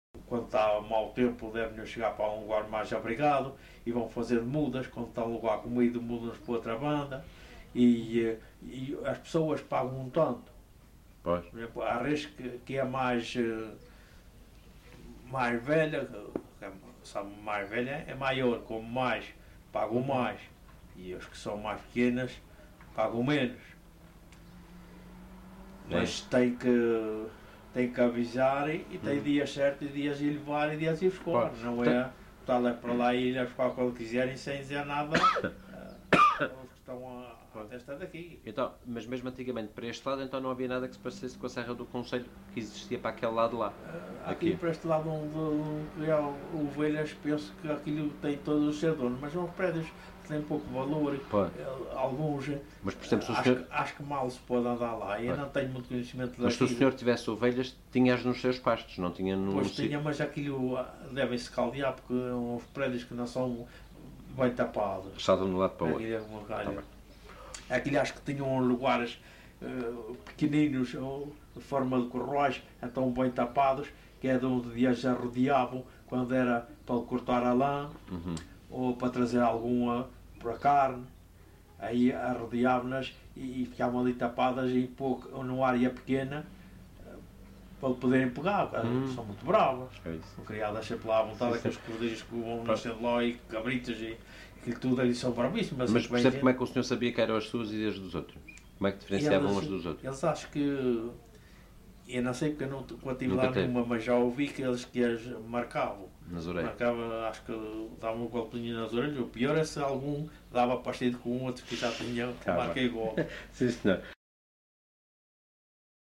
LocalidadeRibeira Seca (Calheta, Angra do Heroísmo)